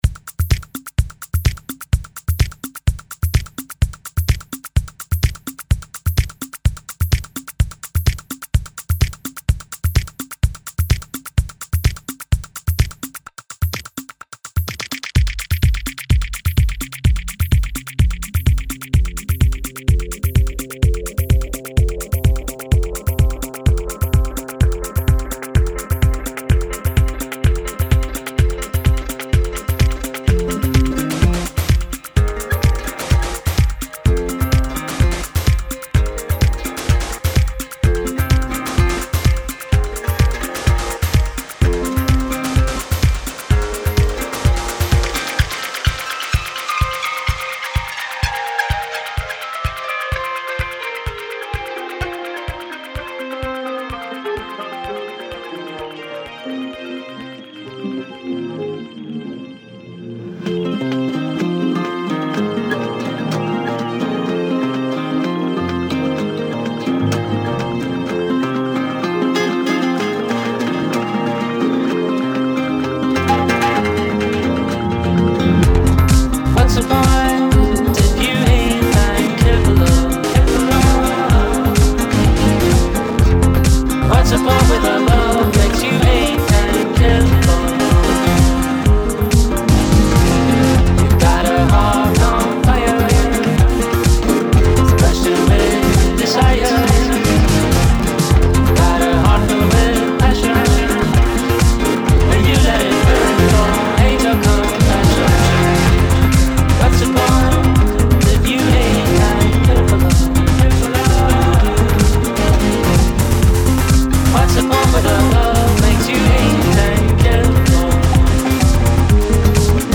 atmospheric
electro track